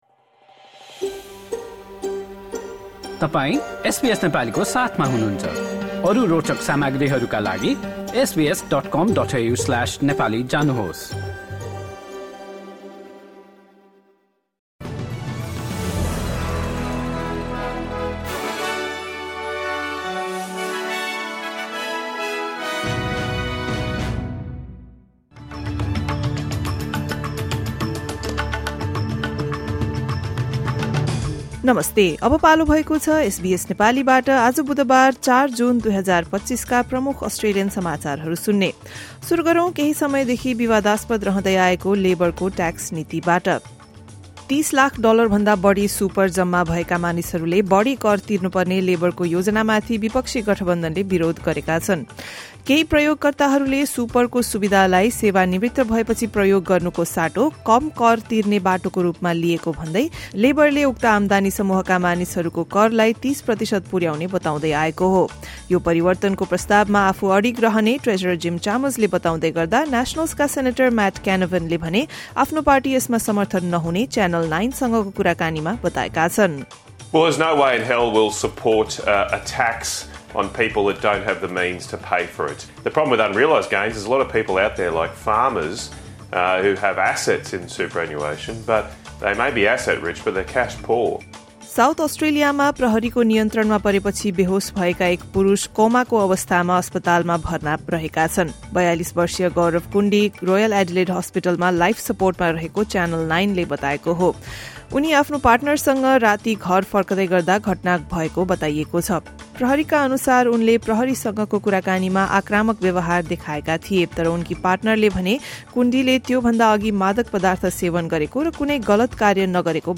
एसबीएस नेपाली प्रमुख अस्ट्रेलियन समाचार: बुधवार, ४ जुन २०२५